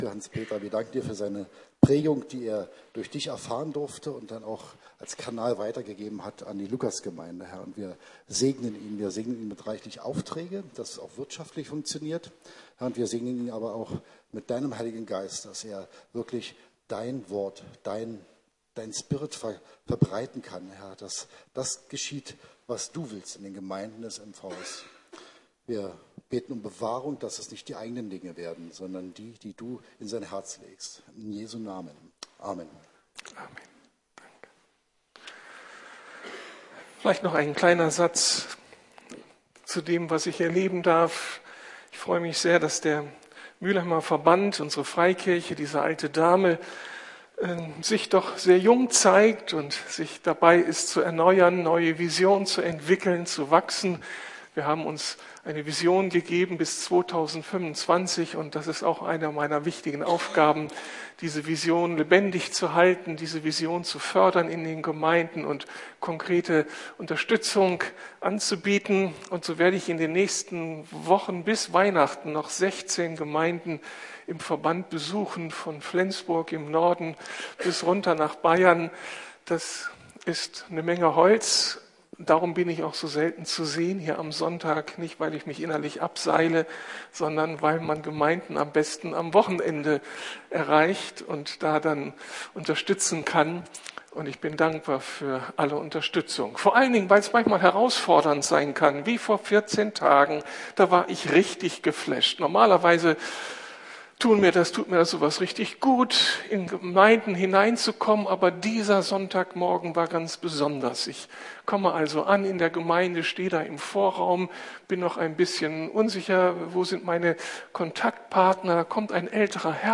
10 Worte des Lebens (Teil 6) ~ Predigten der LUKAS GEMEINDE Podcast